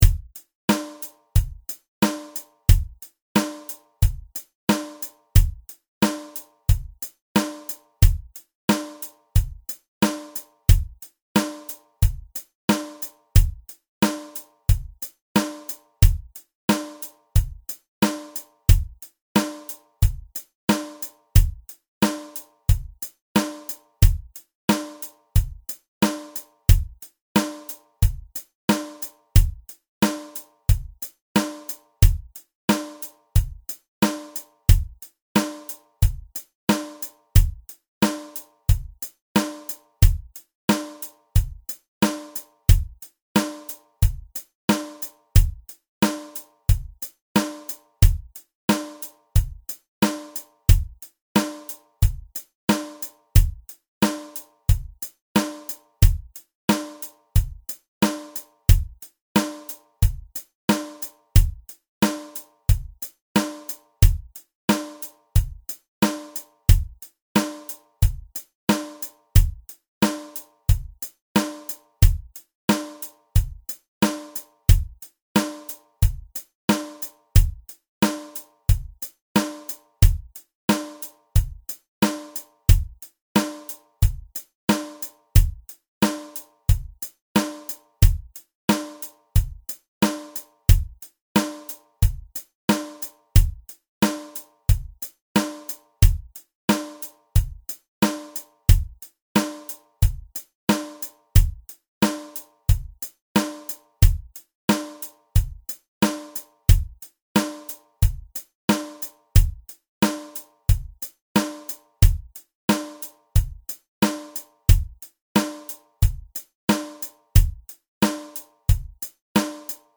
Song Drum Track 060 BPM 070 BPM 080 BPM 090 BPM 100 BPM 110 BPM 120 BPM 130 BPM 140 BPM 150 BPM 160 BPM Other Charts For The Band Modern Band Keyboard Horns Guitar Bass